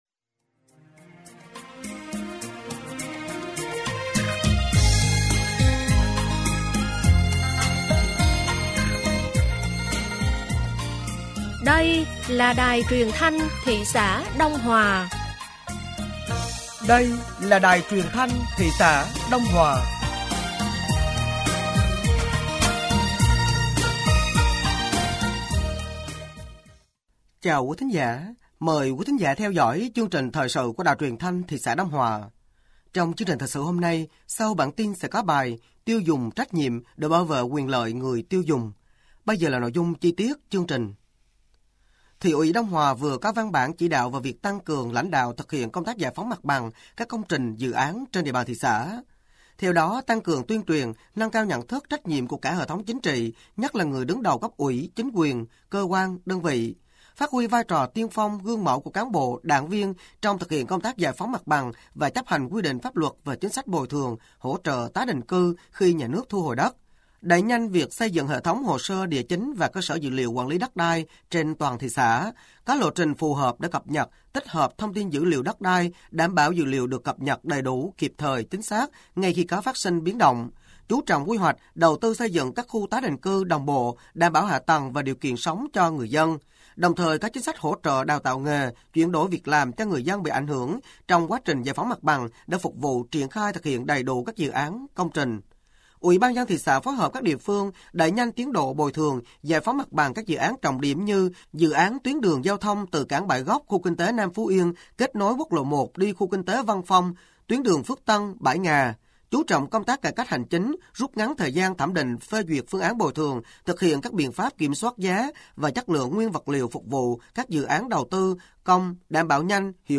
Thời sự tối ngày 05 và sáng ngày 06 tháng 4 năm 2025